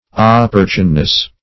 opportuneness - definition of opportuneness - synonyms, pronunciation, spelling from Free Dictionary
Op`por*tune"ness, n.